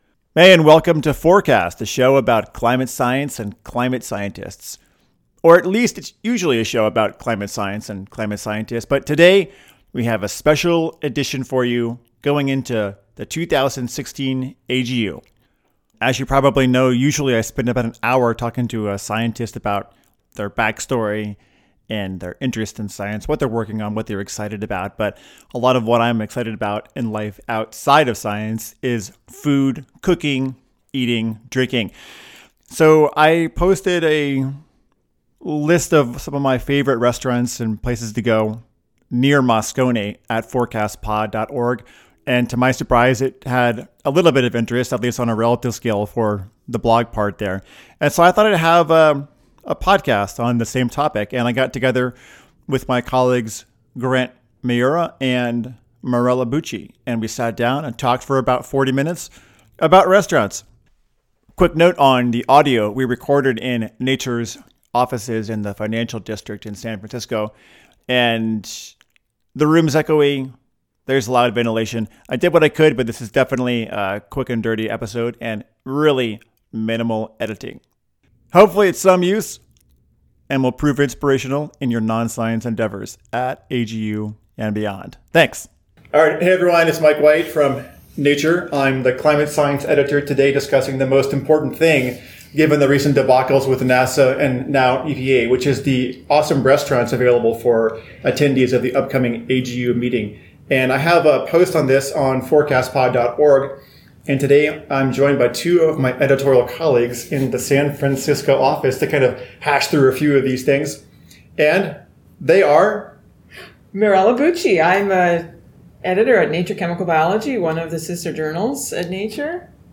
We recorded in the loud, reverb-laden Nature office, so the audio quality is horrendous. And I did virtually none of my usual editing.